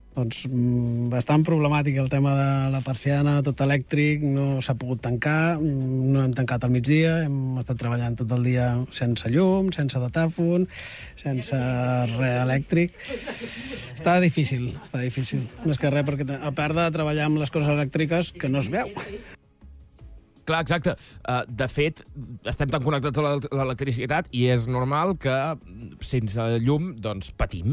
Al Supermatí hem fet un programa especial per poder recollir els testimonis d'alcaldes, ciutadans i empresaris de la comarca per veure com van afrontar les hores sense llum d'aquest dilluns